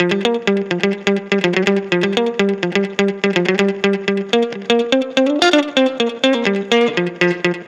Electric Guitar 13.wav